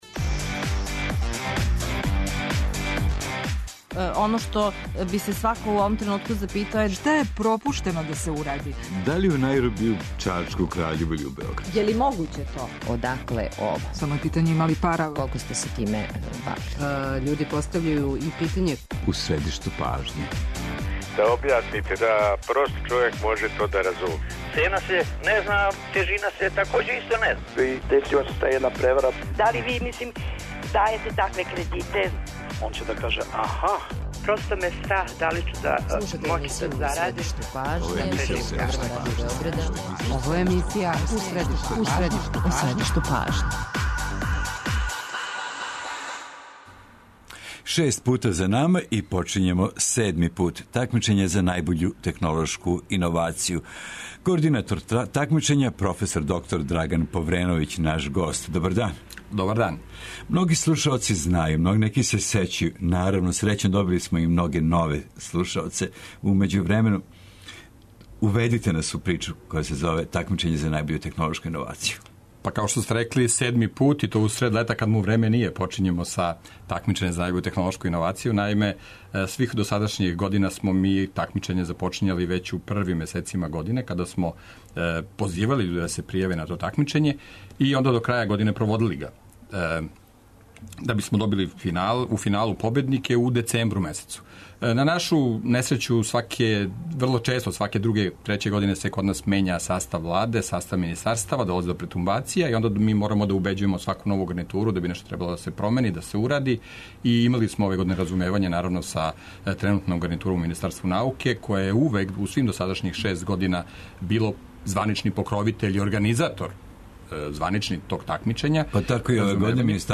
Разговараћемо такође и са некима од оних којима је ово такмичење помогло да оснују своје фирме.